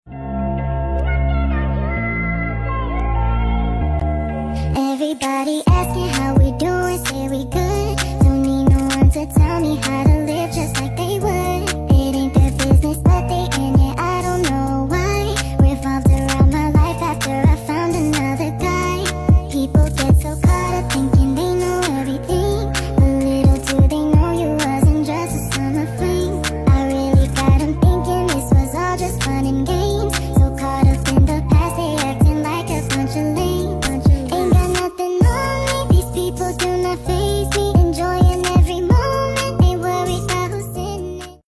Speed Up